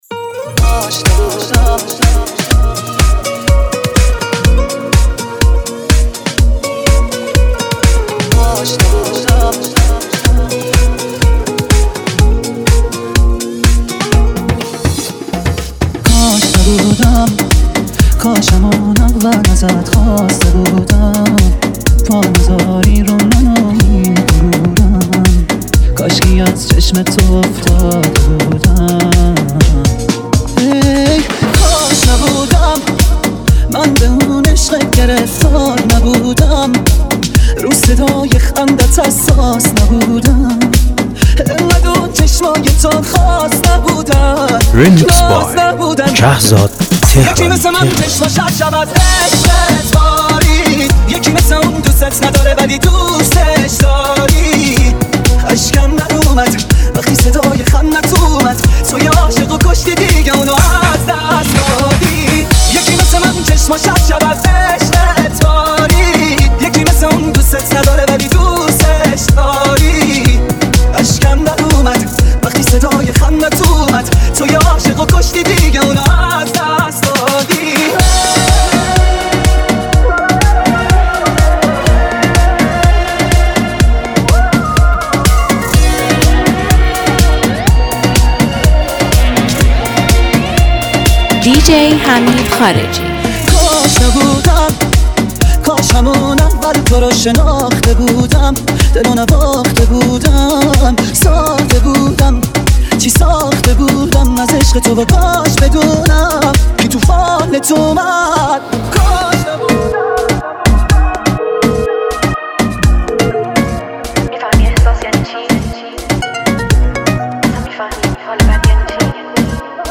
با حس و حال غمگین آهنگ، فضایی رمانتیک و عاطفی خلق می‌کند.